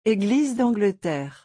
Pronounced: Ay-Gleeze-Don-Glah-Tear
eglise-dAngleterre-pronunciation-french.mp3